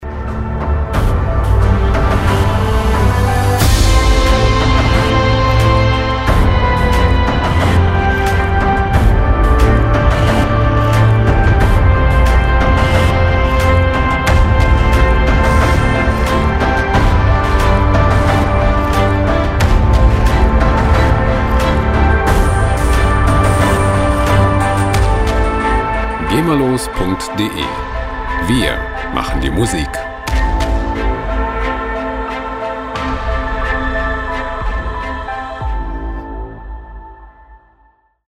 epische Musikloops lizenzfrei
Musikstil: Soundtrack
Tempo: 90 bpm